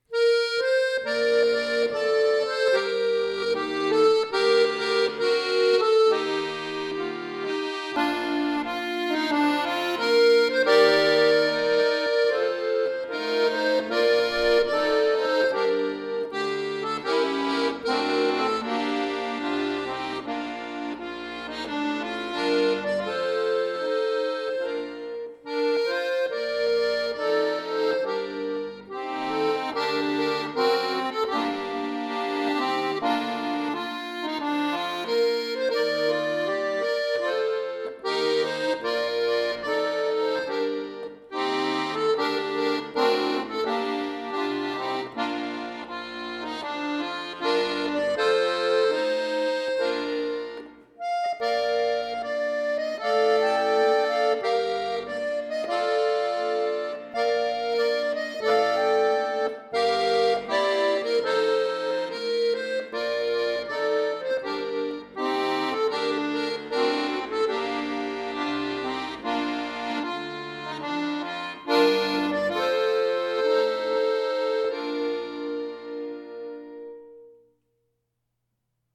Traditioneller Folksong